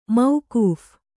♪ maukūph